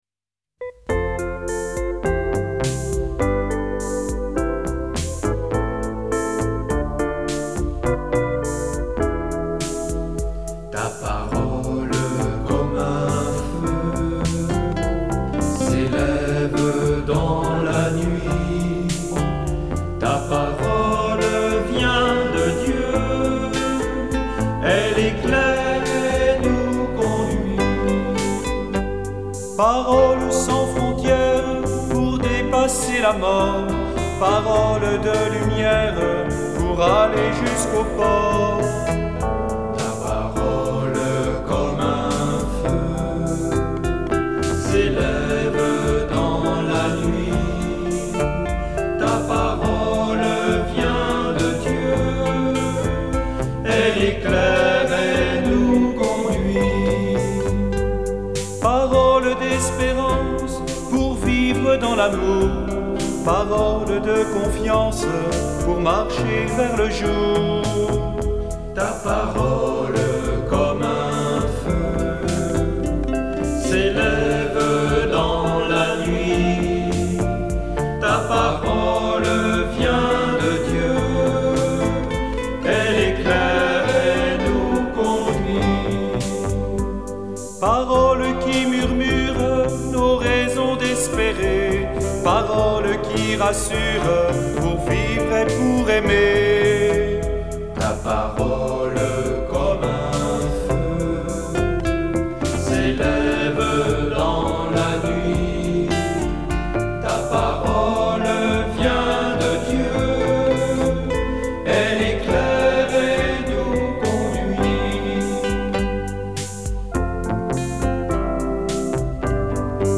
♬ Chant